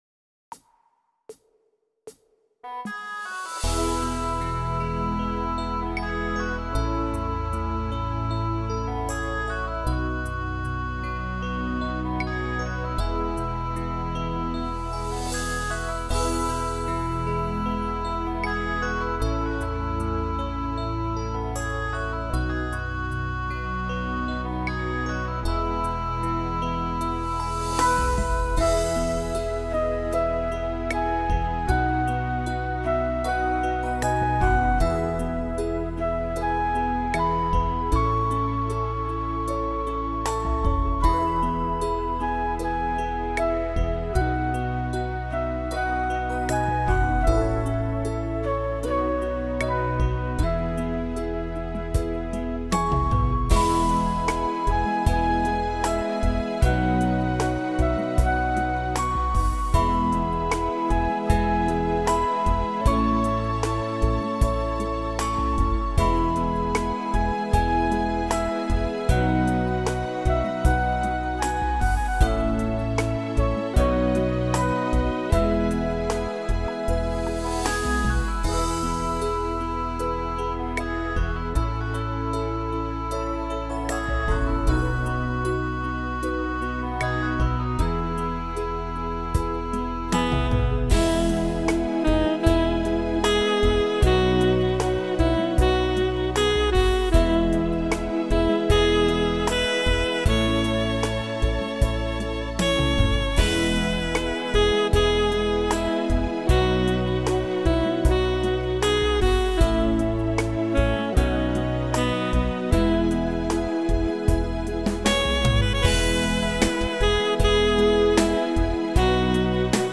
bagpipe